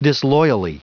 Prononciation du mot disloyally en anglais (fichier audio)
Prononciation du mot : disloyally